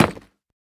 Minecraft Version Minecraft Version latest Latest Release | Latest Snapshot latest / assets / minecraft / sounds / block / nether_bricks / break1.ogg Compare With Compare With Latest Release | Latest Snapshot
break1.ogg